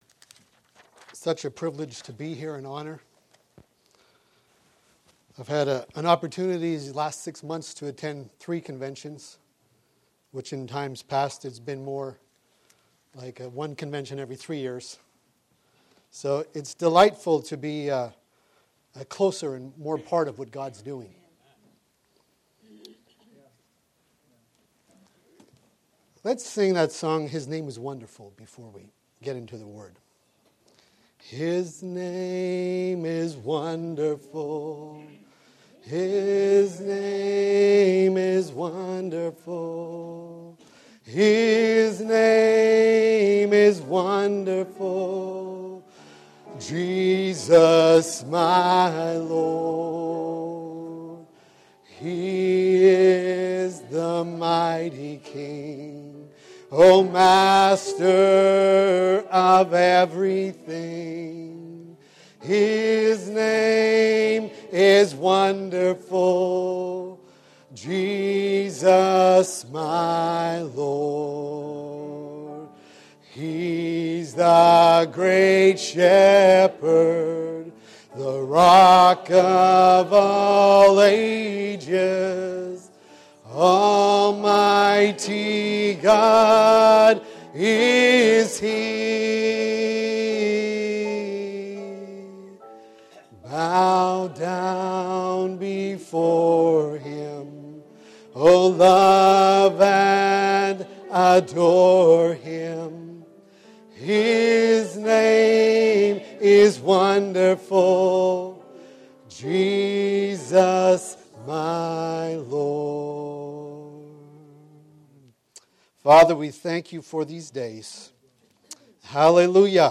Shepherds Christian Centre Convention